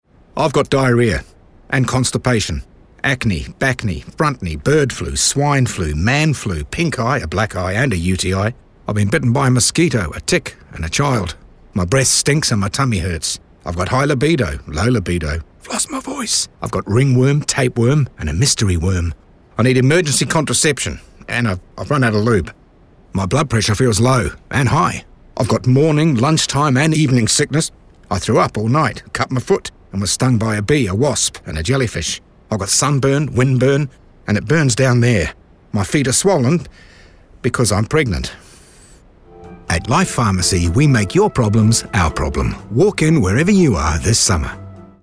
The radio execution carries the idea through sound, with a Life pharmacist rattling off a chaotic list of customer ailments as though they’re experiencing them firsthand.